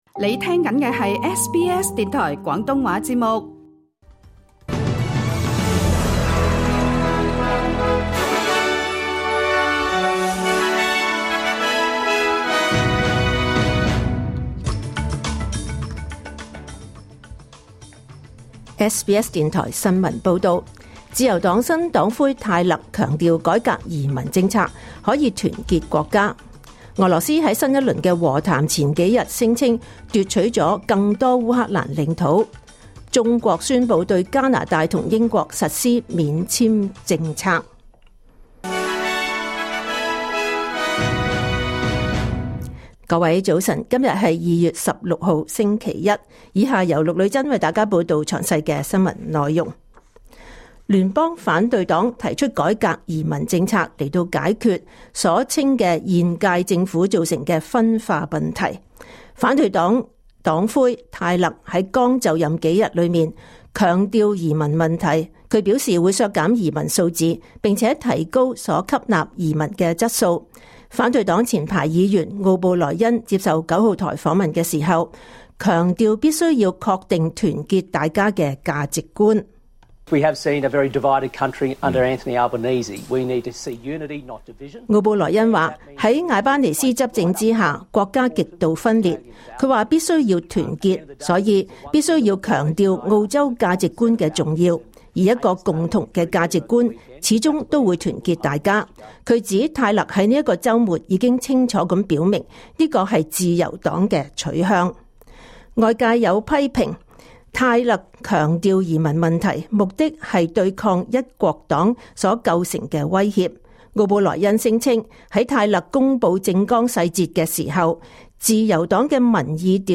2026 年 2 月 16 日SBS廣東話節目九點半新聞報道。